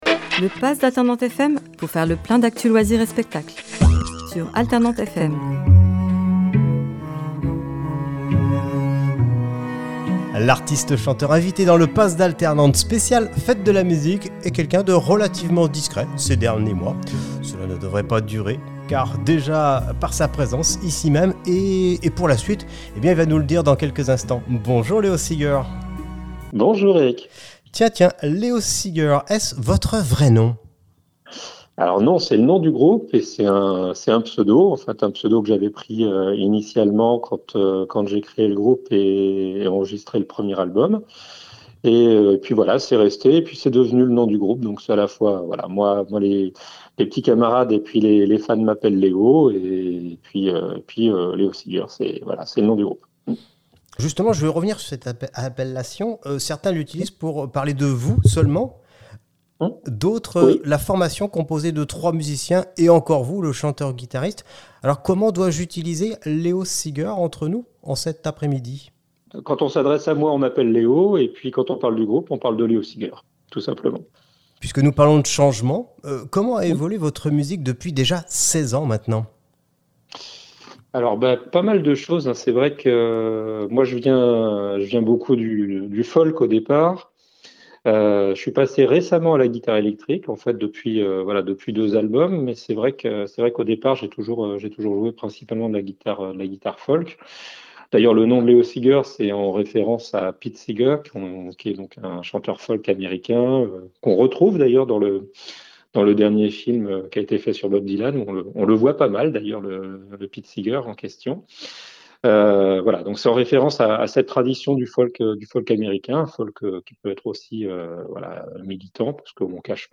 itw_alternantes_juin2025.mp3